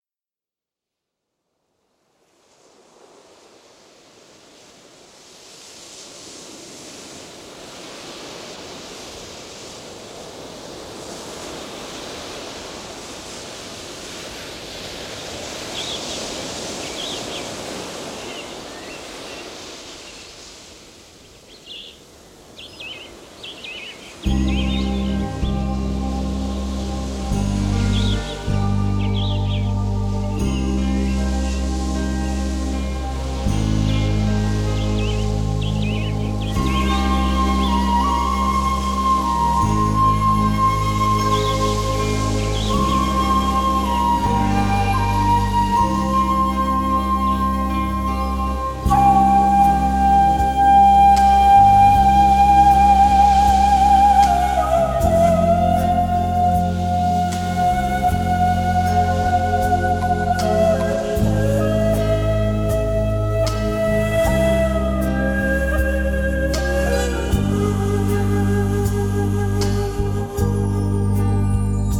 应用世界音乐风格的编曲，加上韩国国
了以韩乐器乐『伽倻琴、奚胡、大令、杖鼓、牙筝、
ocraina....』，还包括中国二胡、
以及特殊的乐器伽倻琴和Ocarina等，